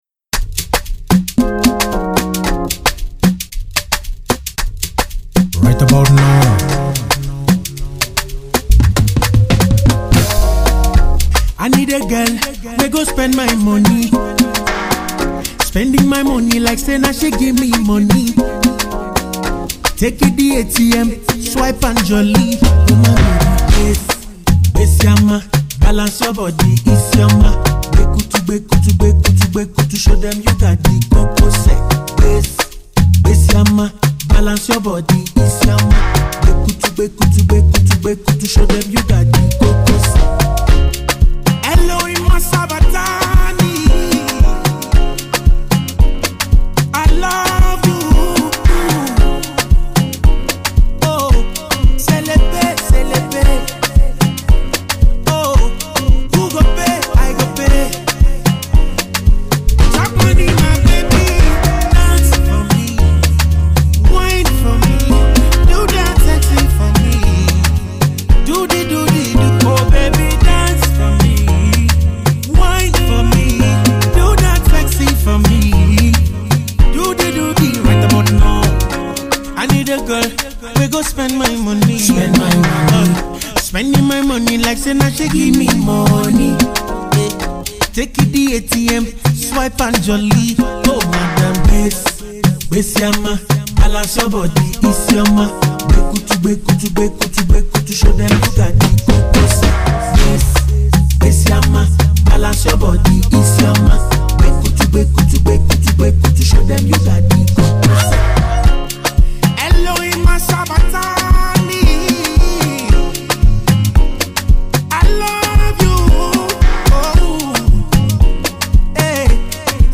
dirty ballad song